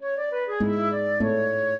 flute-harp
minuet5-11.wav